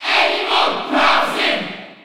Category:Crowd cheers (SSB4) You cannot overwrite this file.
Villager_Cheer_French_NTSC_SSB4.ogg.mp3